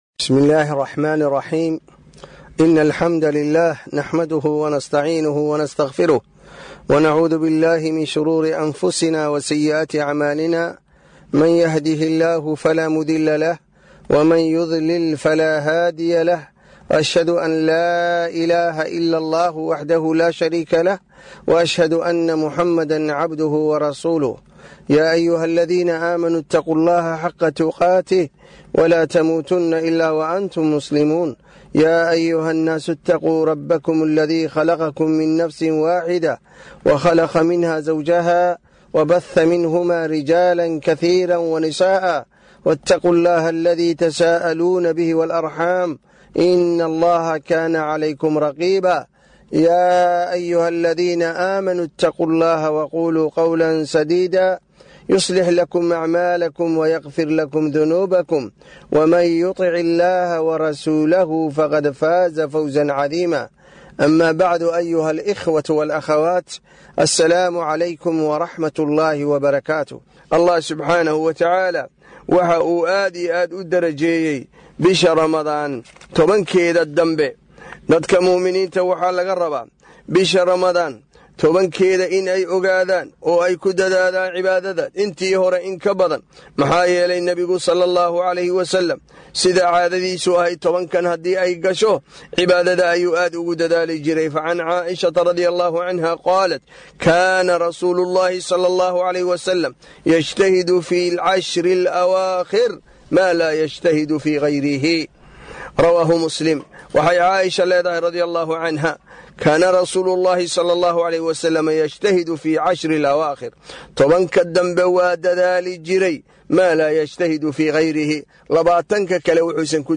Muxaadaro